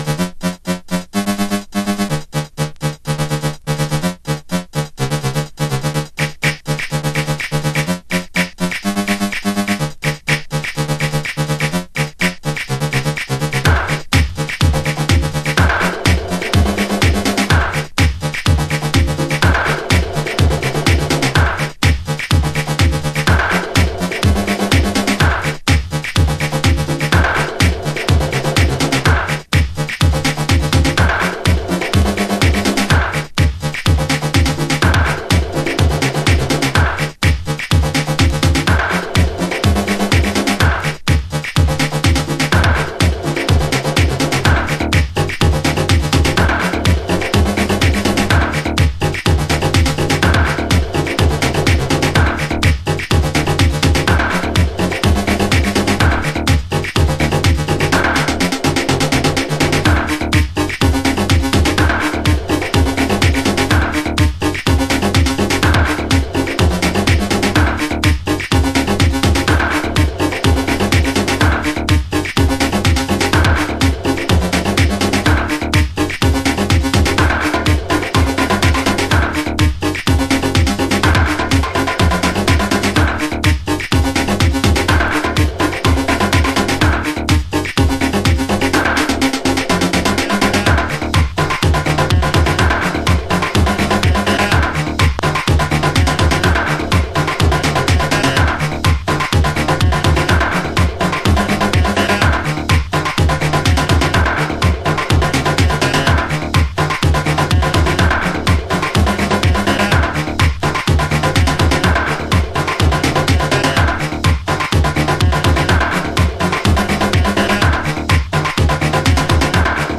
TOP > Chicago Oldschool
シンセもビートも暴れ放題、ジャンクなマッドネスを秘めたジャックハウス。